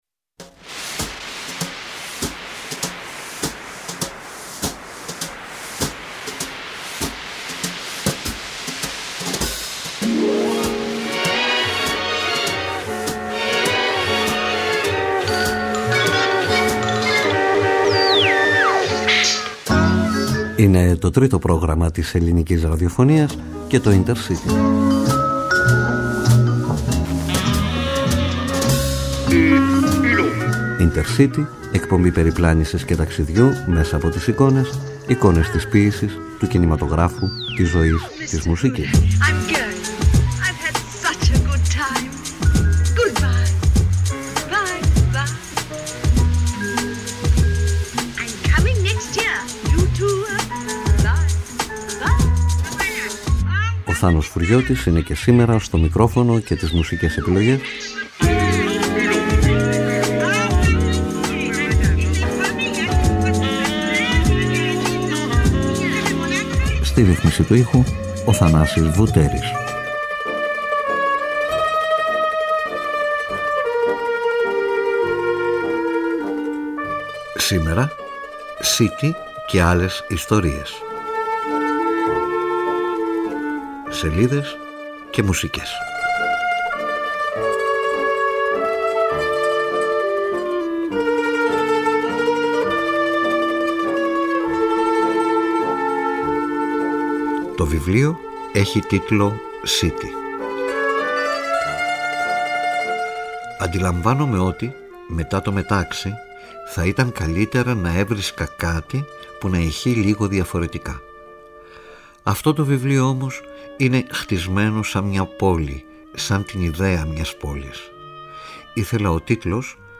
CITY ΚΑΙ ΑΛΛΕΣ ΙΣΤΟΡΙΕΣ (σελίδες και μουσικές)